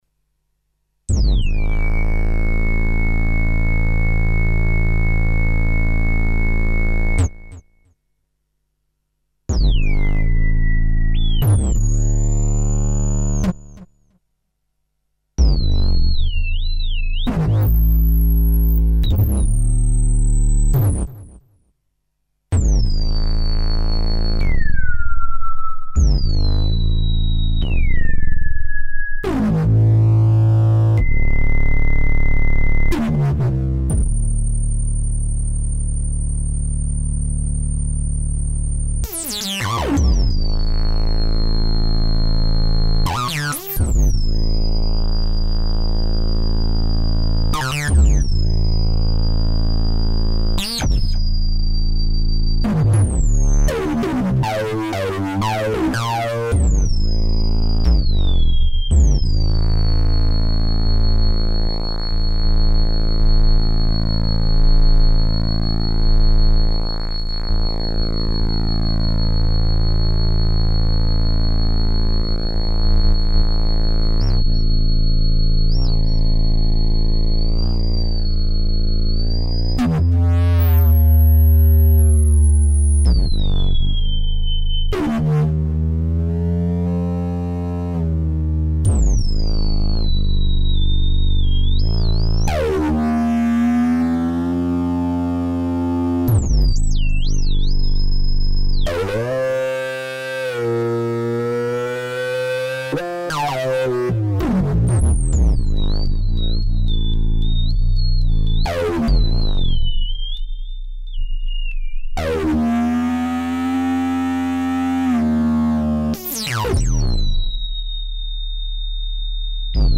This is just me double tracking a fuck around on my synth.
Its pretty grating, but I should work on this to play live.
synth_solo.mp3